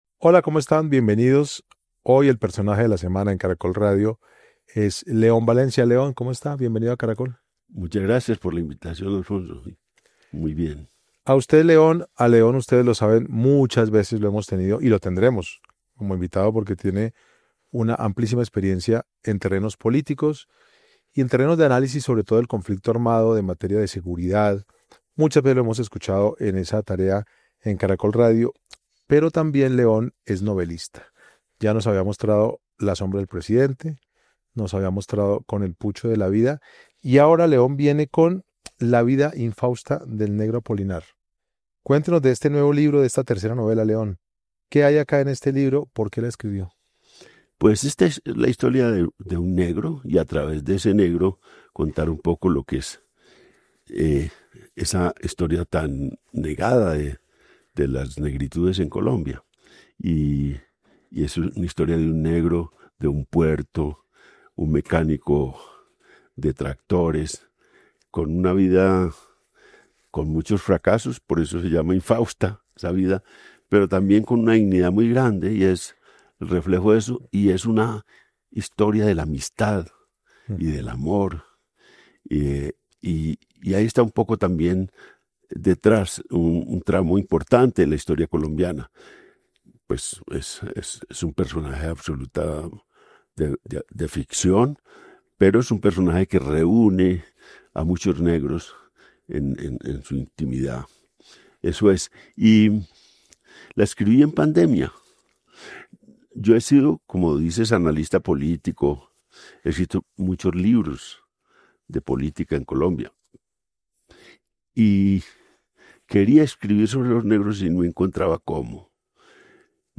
En El Personaje de la Semana, el analista político y escritor León Valencia habló con Caracol Radio sobre La vida infausta del negro Apolinario, su más reciente novela.